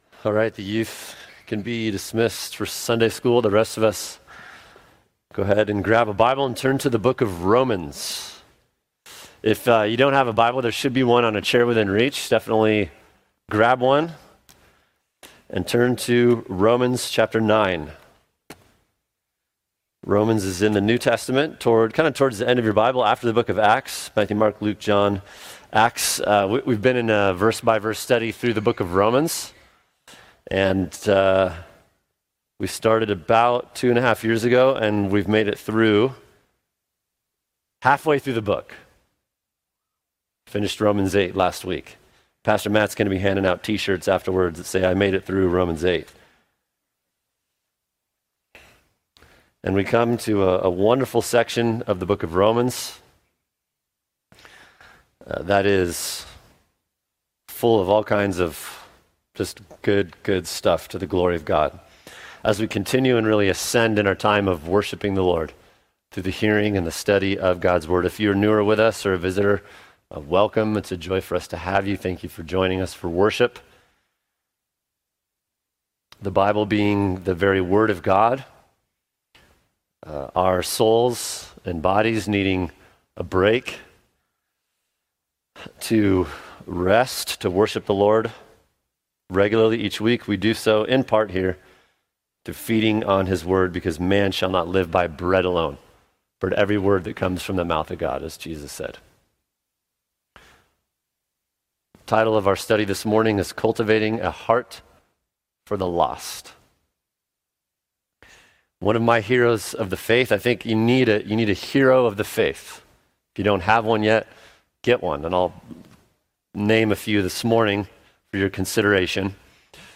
[sermon] Cultivating a Heart for the Lost Romans 9:1-5 | Cornerstone Church - Jackson Hole